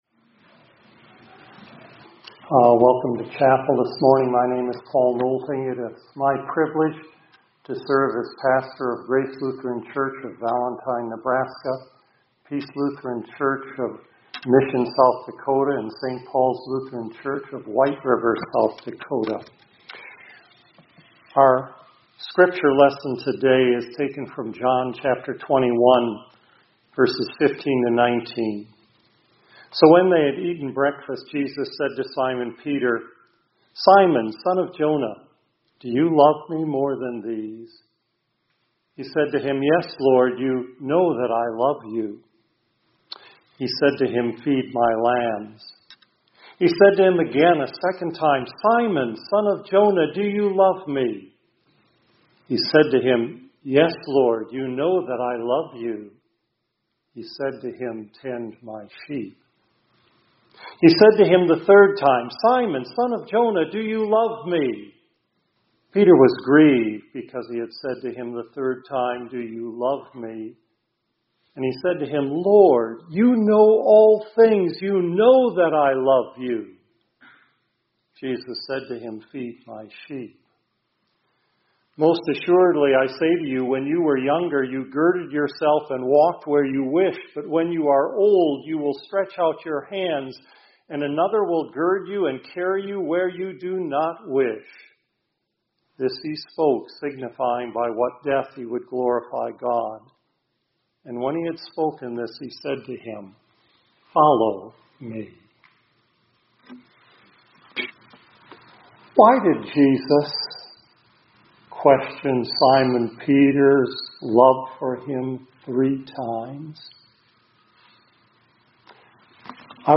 2025-04-30 ILC Chapel — Jesus Strengthens His Disciples’ Weaknesses